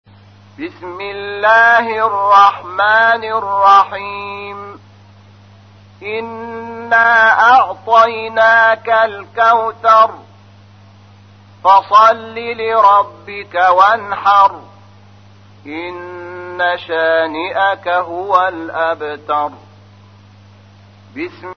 تحميل : 108. سورة الكوثر / القارئ شحات محمد انور / القرآن الكريم / موقع يا حسين